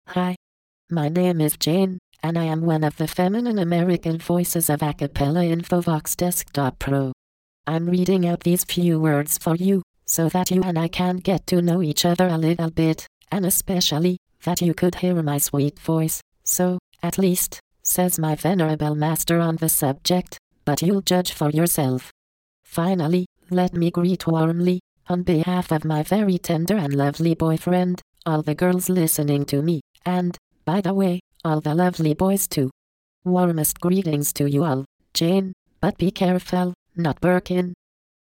Texte de démonstration lu par Jane, voix féminine américaine d'Acapela Infovox Desktop Pro
Écouter la démonstration de Jane, voix féminine américaine d'Acapela Infovox Desktop Pro